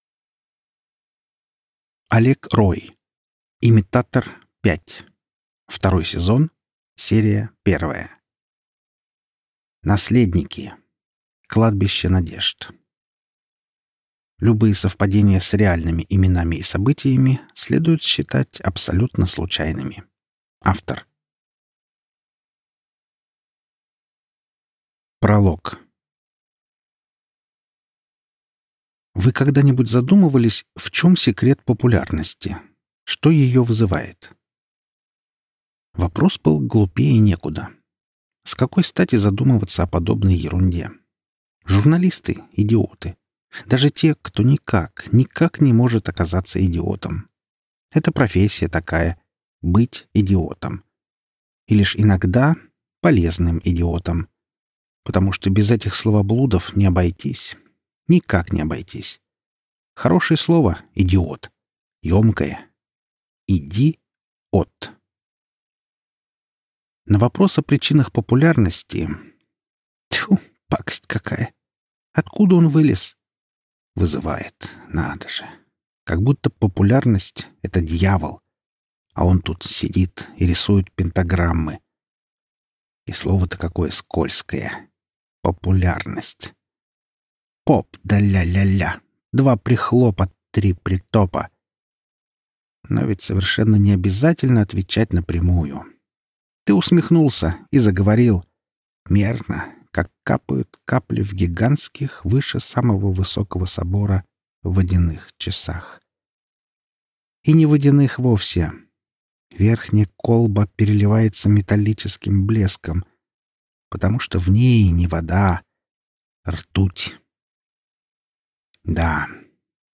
Аудиокнига Имитатор. Книга пятая. Наследники | Библиотека аудиокниг